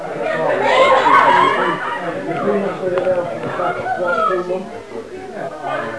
Party.aif
party.aif